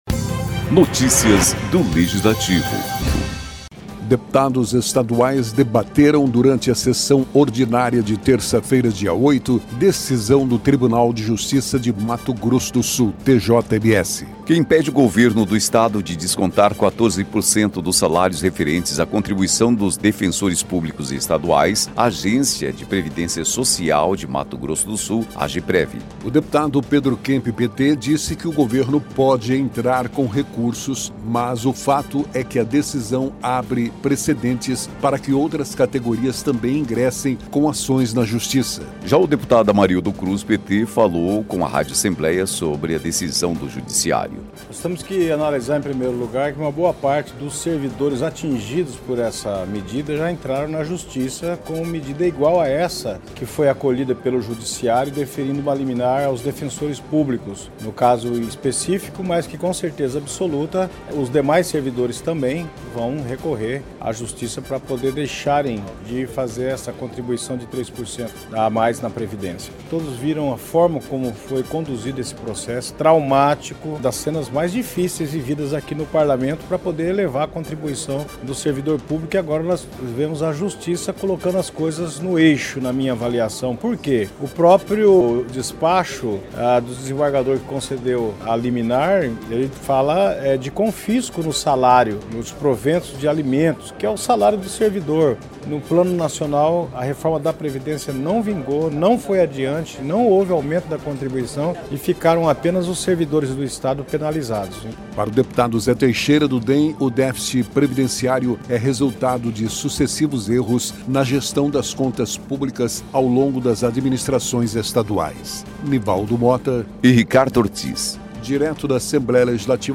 Deputados estaduais debateram, durante a sessão ordinária desta terça-feira (8), decisão do Tribunal de Justiça de Mato Grosso do Sul (TJ-MS) que impede o Governo do Estado de descontar 14% dos salários rerentes à contribuição dos defensores públicos estaduais à Agência de Previdência Social de Mato Grosso do Sul (Ageprev).